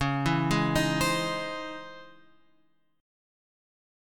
C#mM9 chord {9 7 6 8 x 8} chord